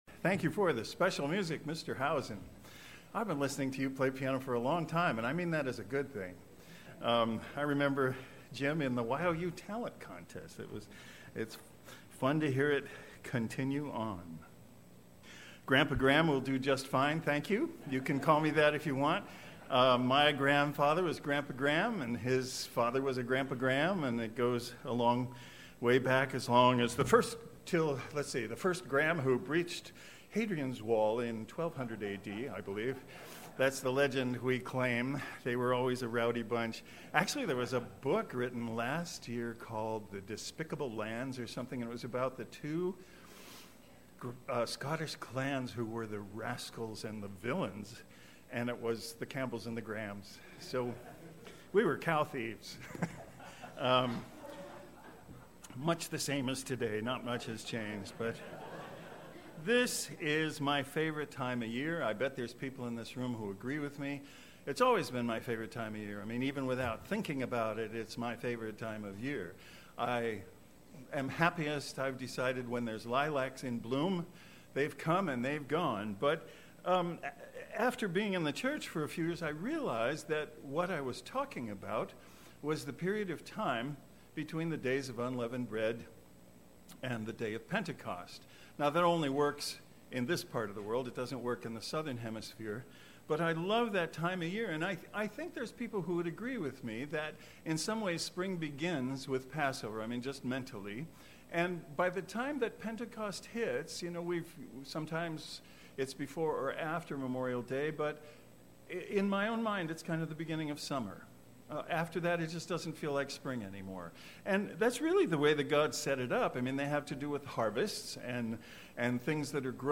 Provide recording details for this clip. Given in Cleveland, OH